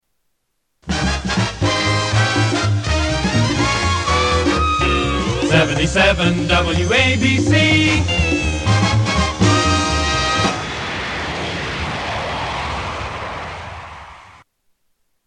Tags: Radio Radio Stations Station I.D. Seques Show I.D